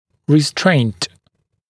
[rɪ’streɪnt][ри’стрэйнт]сдерживание, ограничение